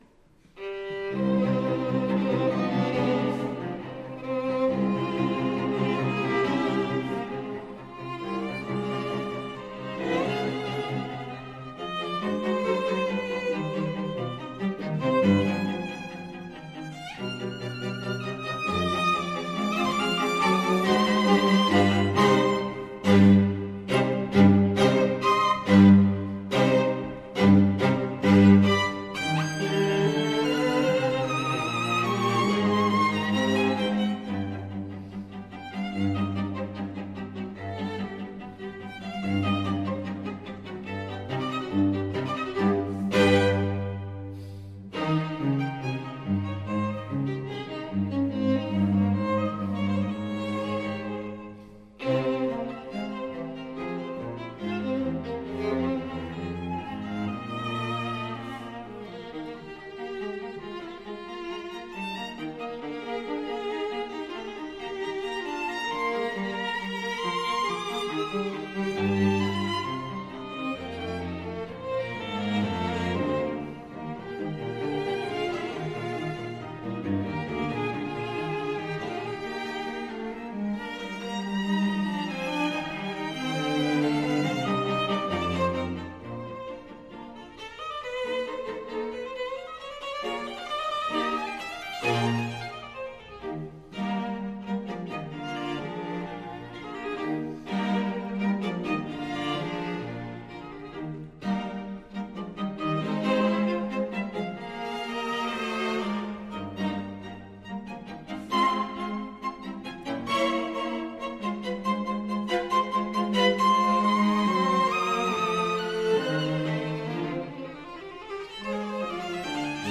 Violin 1 Violin 2 Viola Cello
Style: Classical
Audio: Boston - Isabella Stewart Gardner Museum
Audio: Orion String Quartet